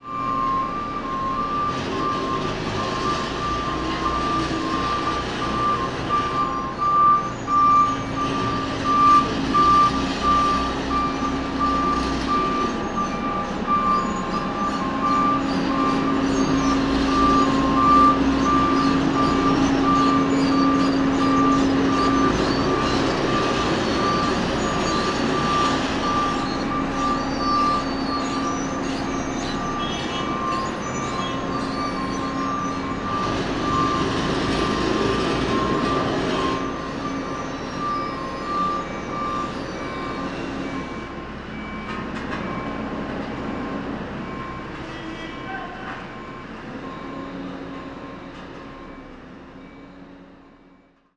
travaux-tunnel.mp3